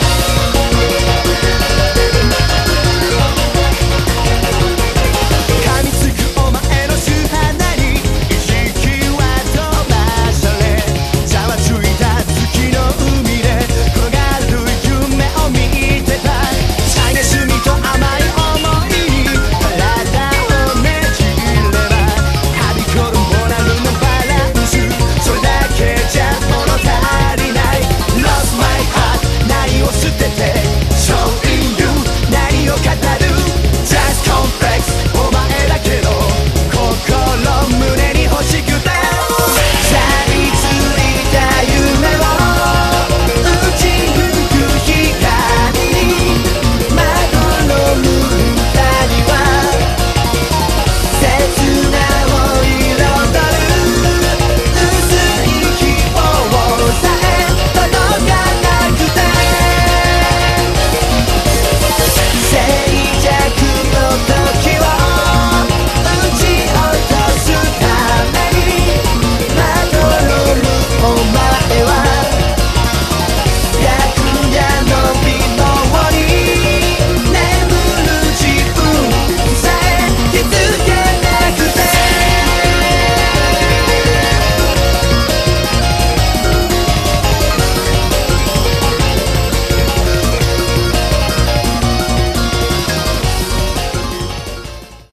BPM170
Audio QualityPerfect (High Quality)
comes this epic J-Tech Rock song.